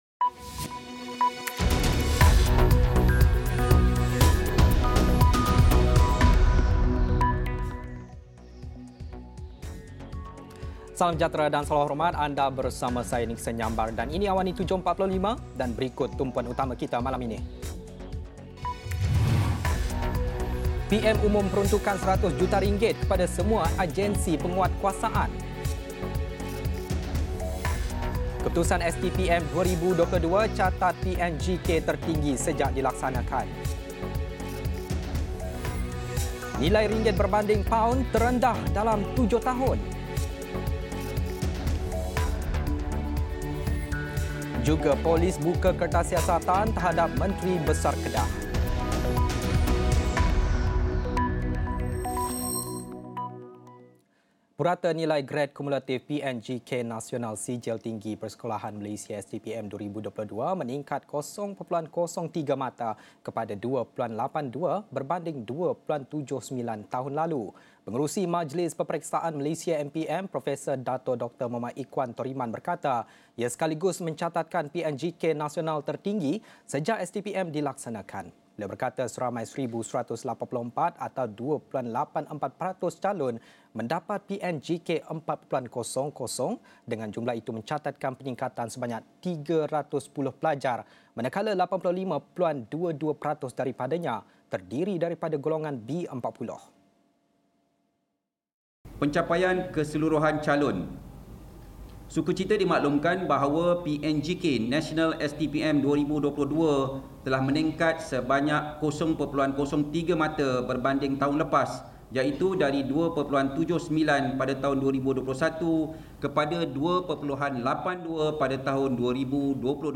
Laporan berita padat dan ringkas